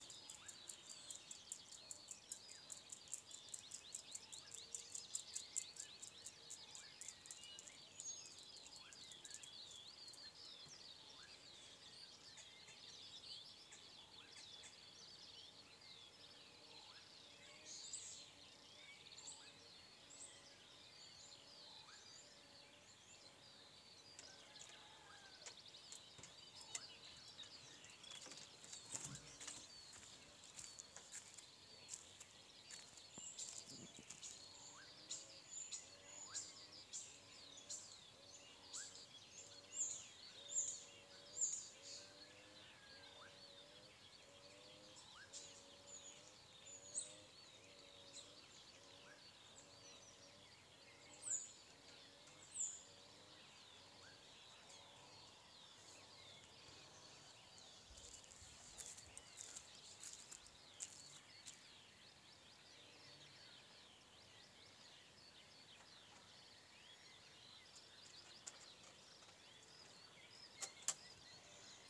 скворец, Sturnus vulgaris
Administratīvā teritorijaKocēnu novads
СтатусПоёт
Примечания Neatpazīstu vienmuļo, divzilbīgo svilpienu.